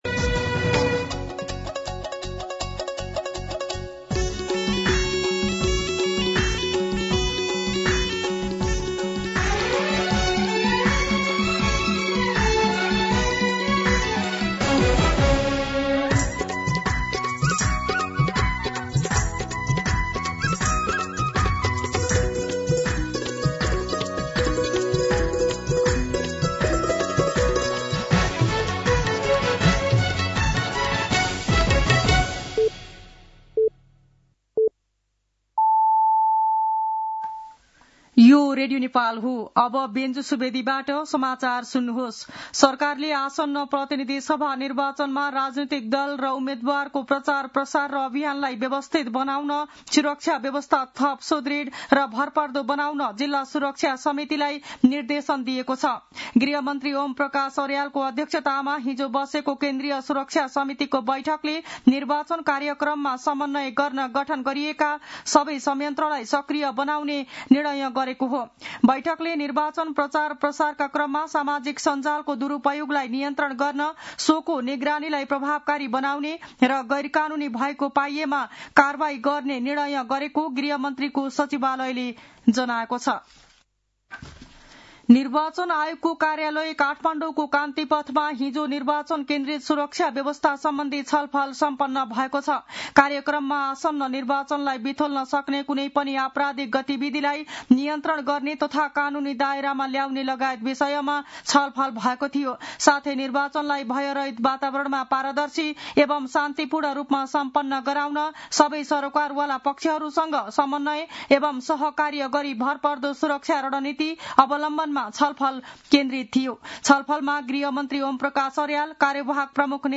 मध्यान्ह १२ बजेको नेपाली समाचार : ८ माघ , २०८२
12-pm-News-10-8.mp3